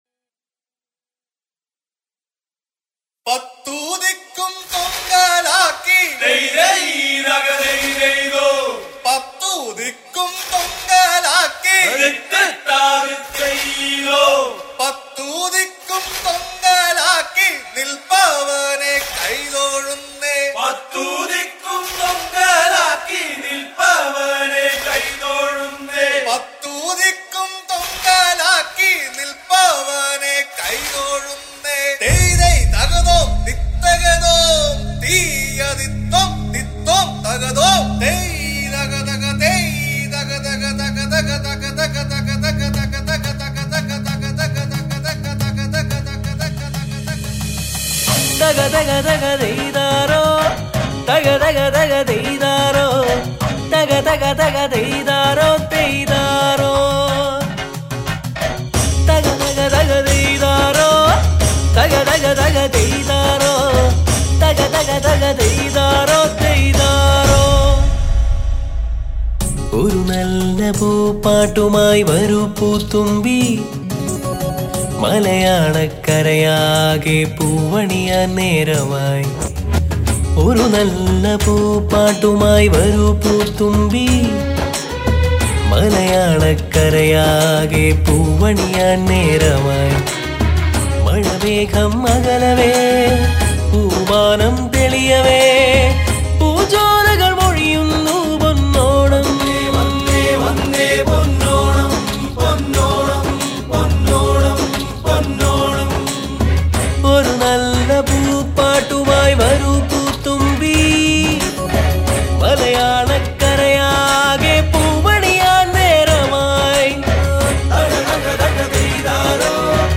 ഇത്രയും നല്ലൊരു ഓണപ്പാട്ട് അടുത്തകാലത്തൊന്നും കേട്ടിട്ടില്ല
ചടുലമായ സംഗീതവും ഗംഭീരമായ പശ്ചാത്തലവും